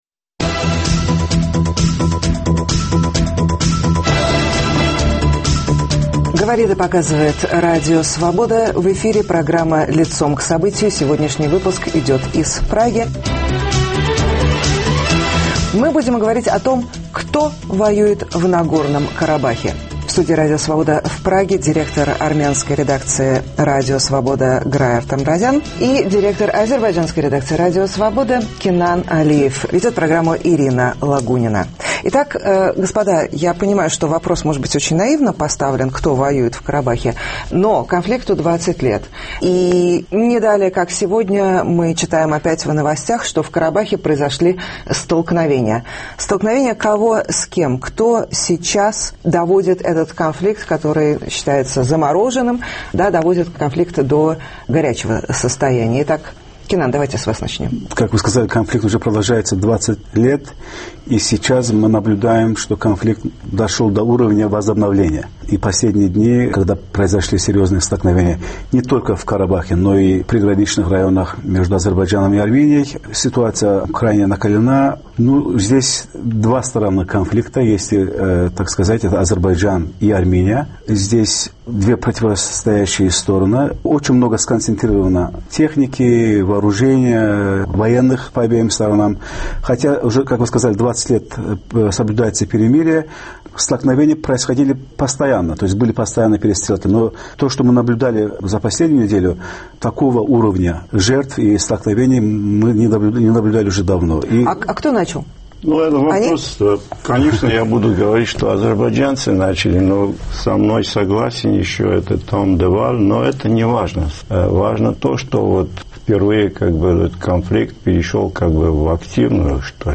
В студии Радио Свобода в Праге